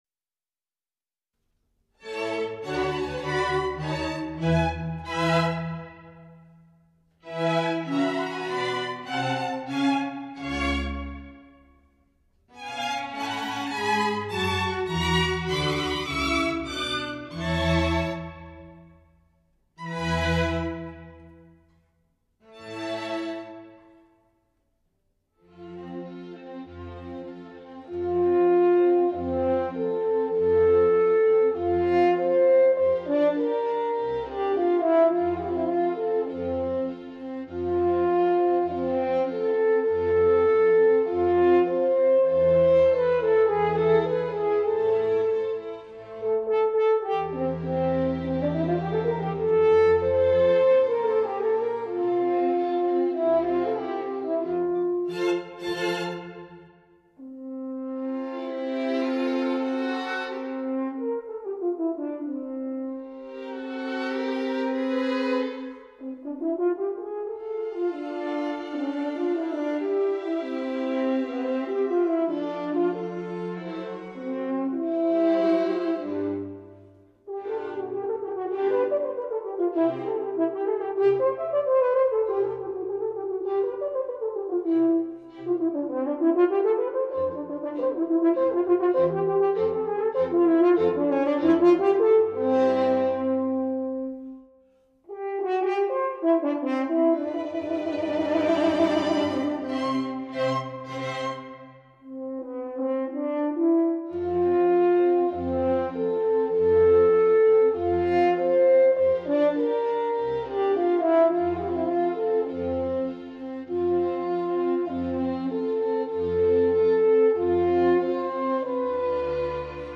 corno